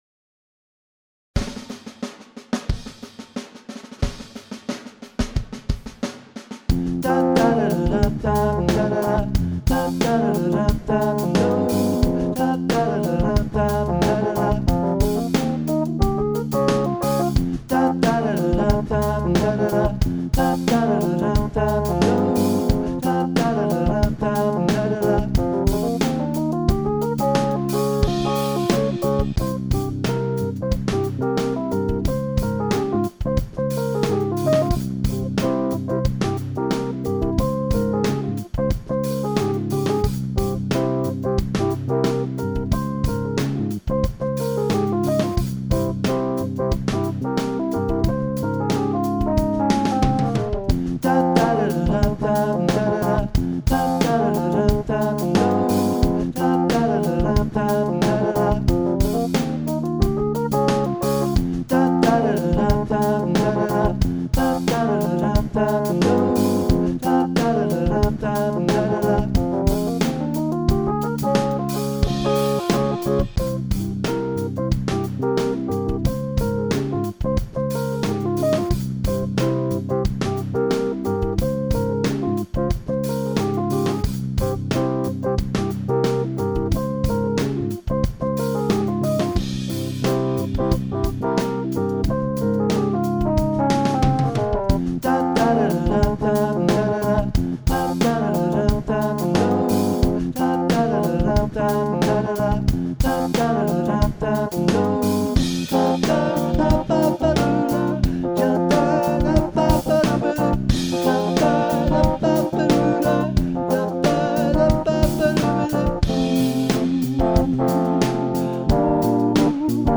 3:20/90bpm